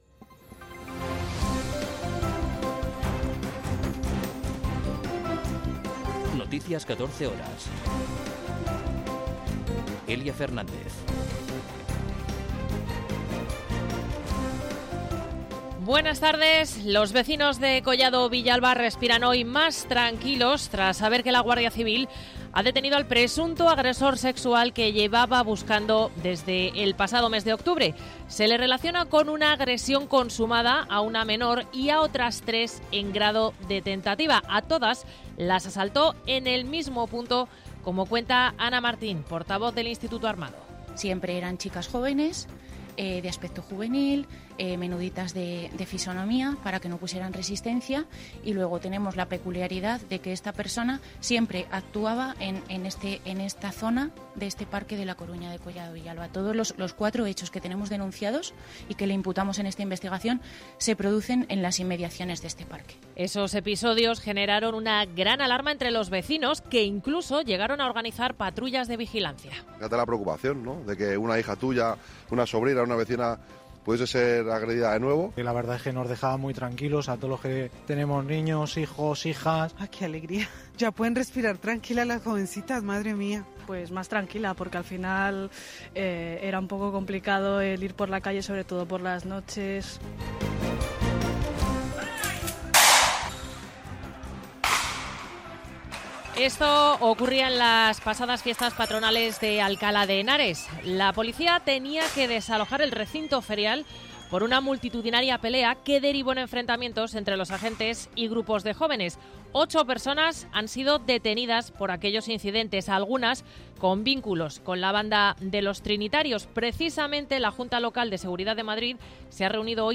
Noticias 14 horas 17.02.2023